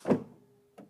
Звуки электросамоката
Звук Натискаємо щоб самокат поїхав але акумулятор розряджений